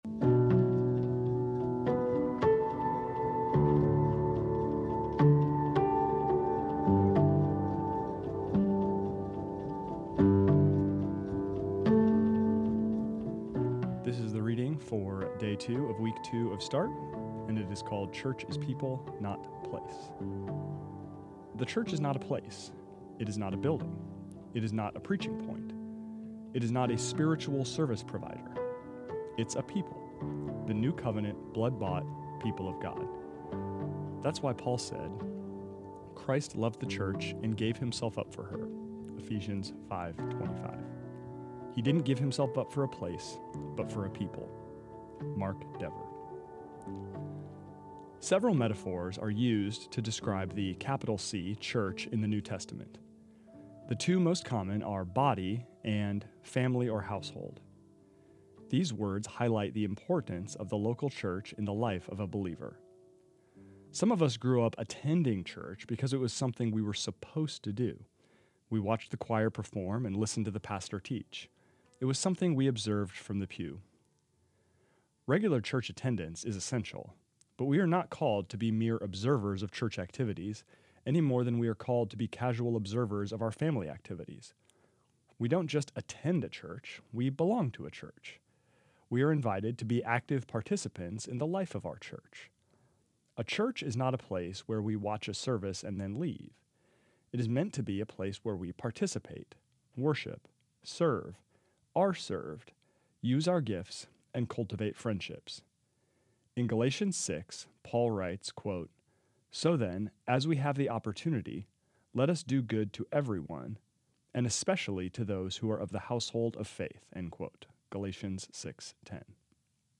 This is the audio recording of the second reading of week two of Start, entitled Church is People, Not Place.